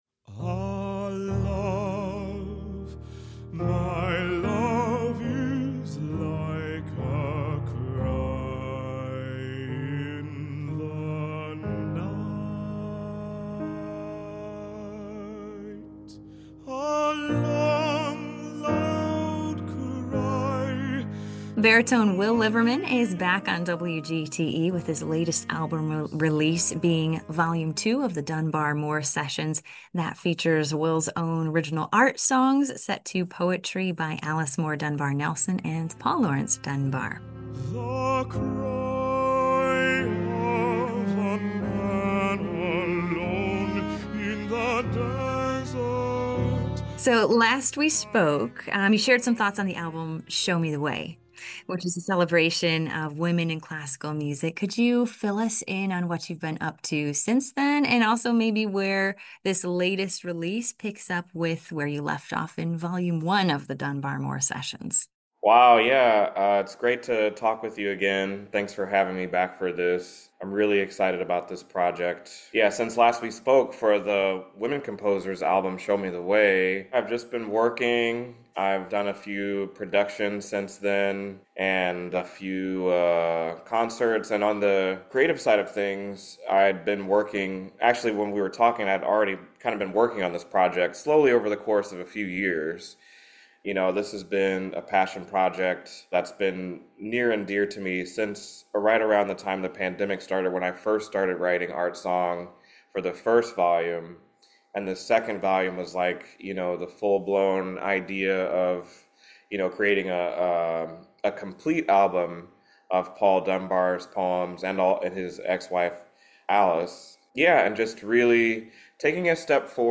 Classical-Conversation-with-Will-Liverman-2025-Export.mp3